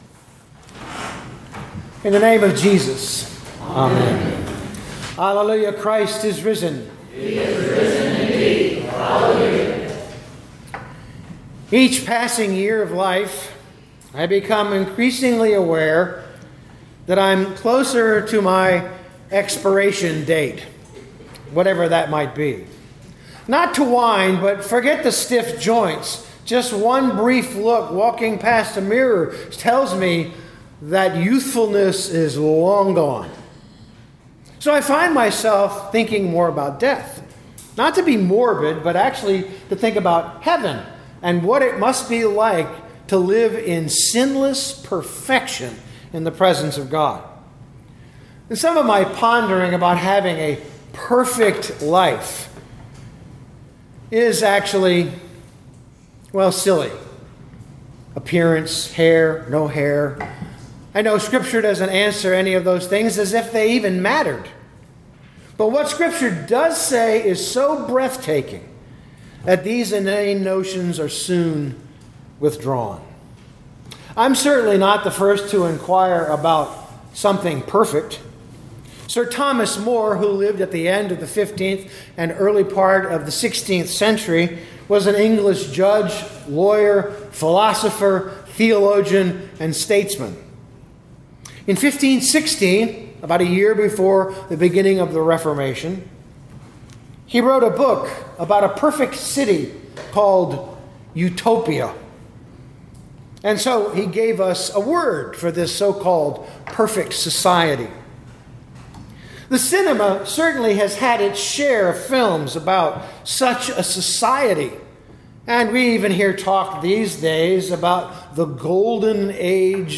2025 Revelation 21:1-8 Listen to the sermon with the player below, or, download the audio.